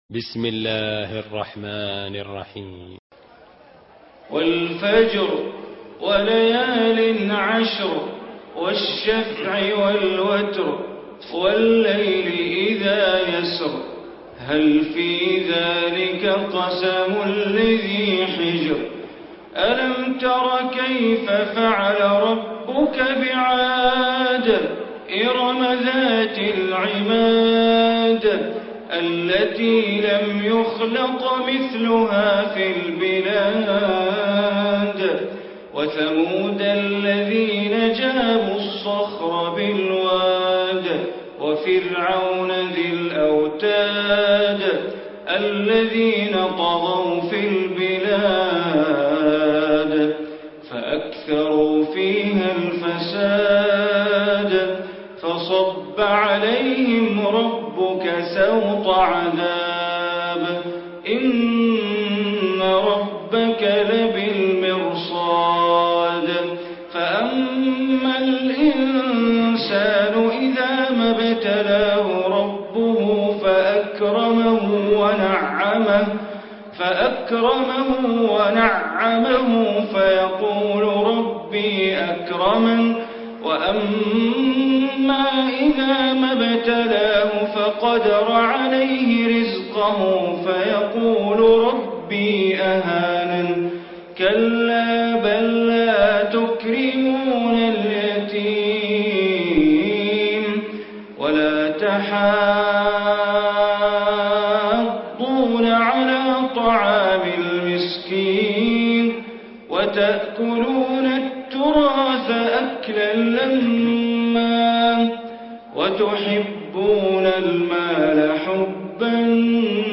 Surah Fajr Recitation by Sheikh Bandar Baleela
Surah Fajr, listen online mp3 tilawat / recitation in Arabic recited by Imam e Kaaba Sheikh Bandar Baleela.